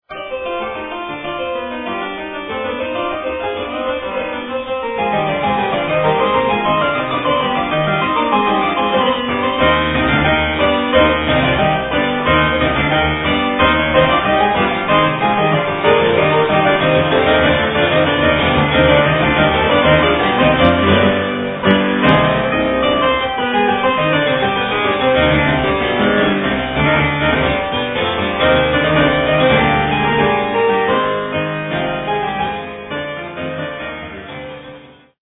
AN HISTORIC REISSUE!